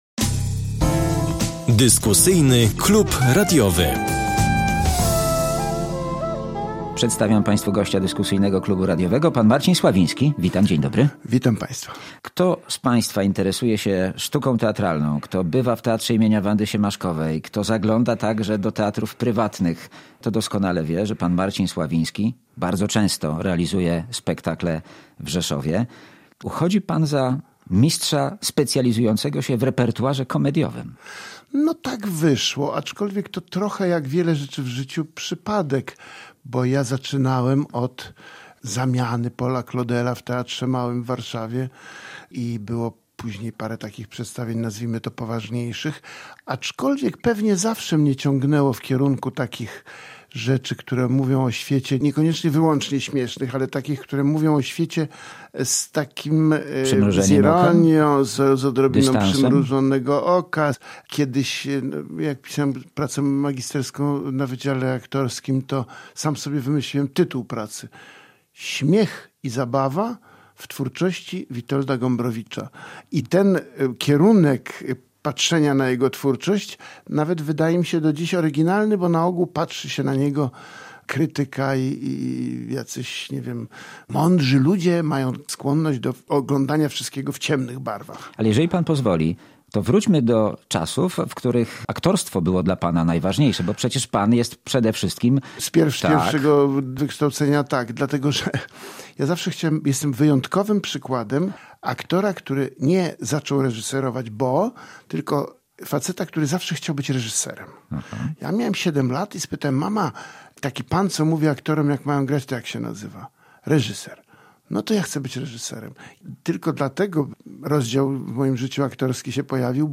Dyskusyjny Klub Radiowy • Aktor i reżyser Marcin Sławiński, uznawany za jednego z najwybitniejszych specjalistów repertuaru komediowego, był gościem Dyskusyjnego Klubu Radiowego.